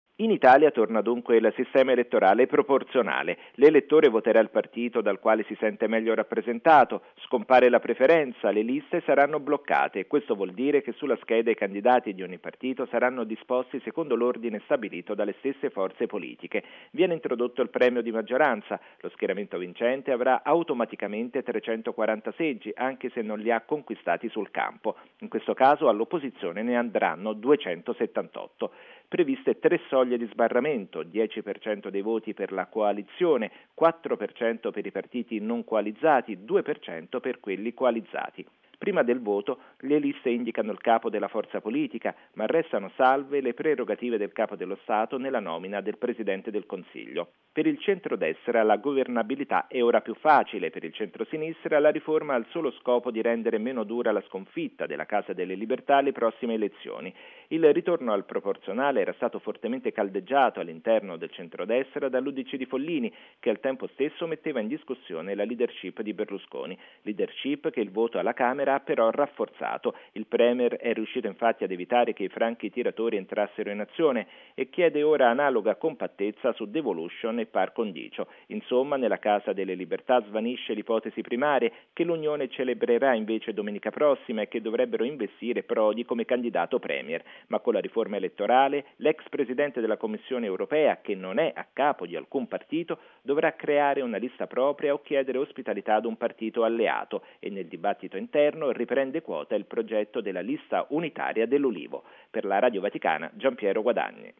E ormai le forze politiche fanno già i conti con i possibili effetti della riforma. Il servizio